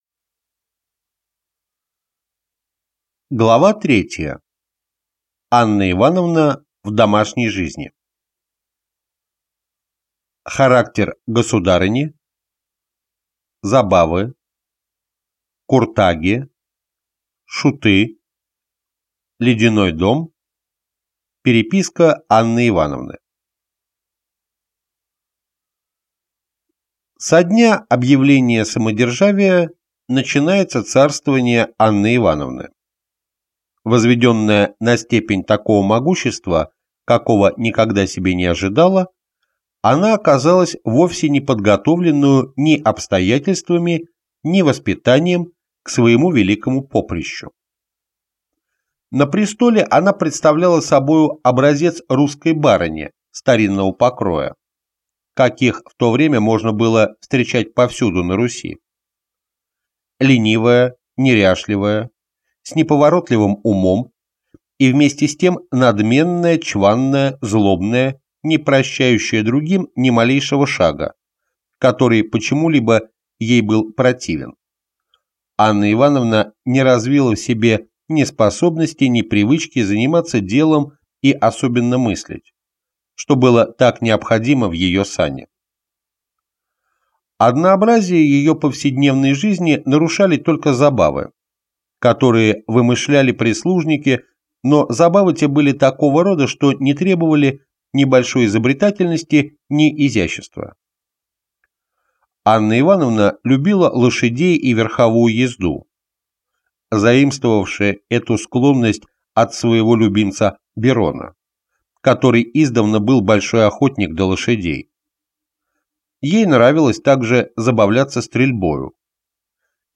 Аудиокнига Русская история. Том 14. Императрица Анна Ивановна и ее царствование | Библиотека аудиокниг